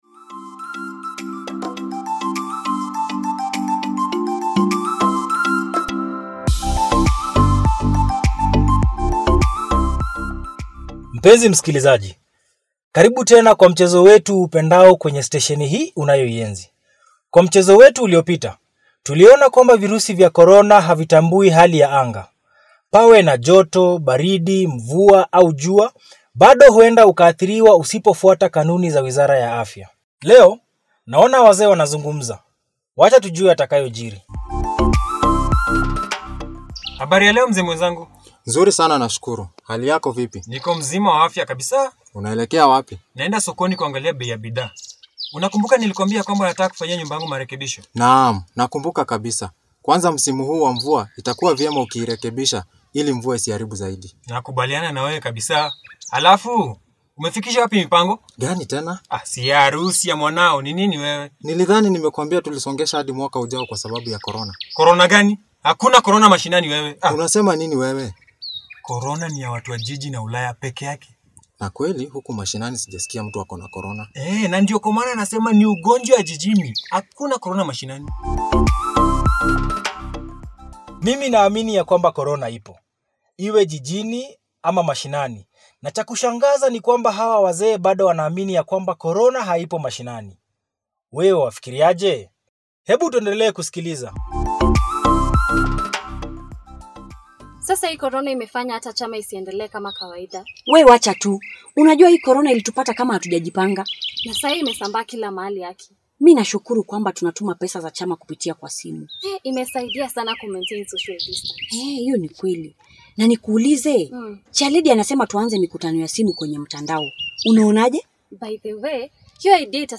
What are some of the myths and misconceptions have you heard about COVID-19 and its management? Listen to this hilarious Swahili radio drama by Linda Arts theater Group.
Episode-3-.-Radio-Drama.mp3